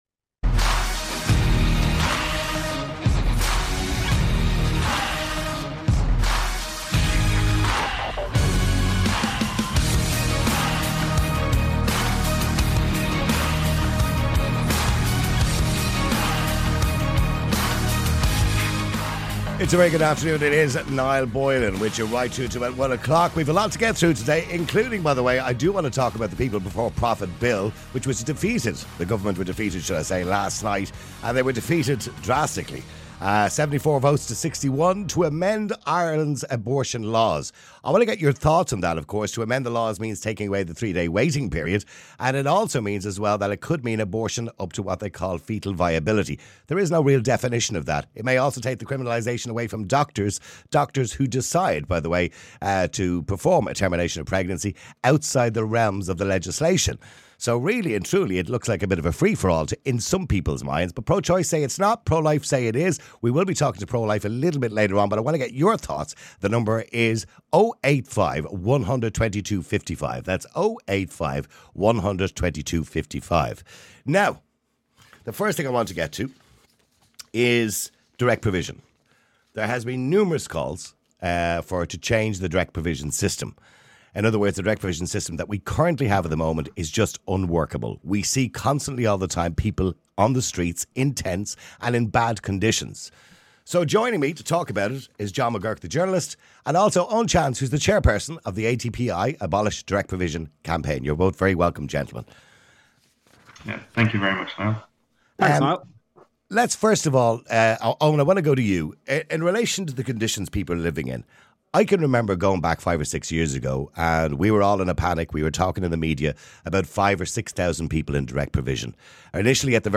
#12 Is Direct Provision Inhumane? (Interviews Audio Only) – My CMS